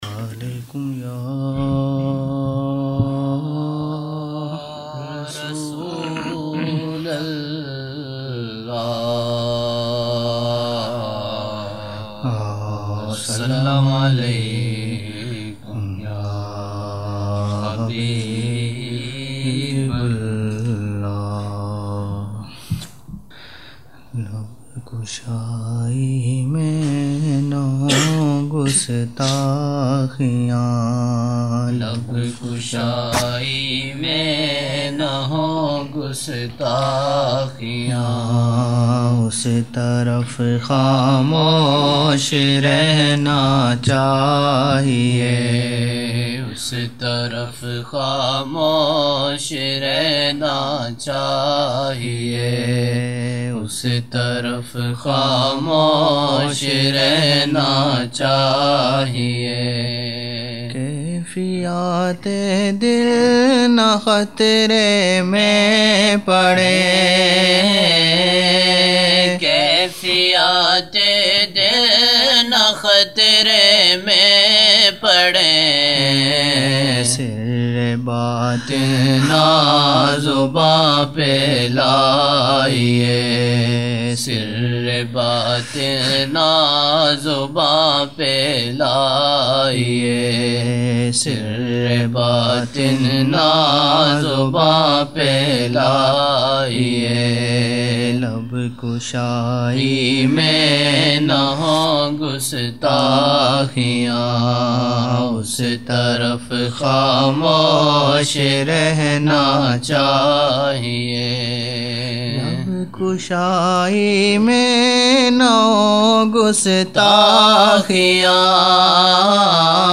19 November 1999 - Maghrib mehfil (11 Shaban 1420)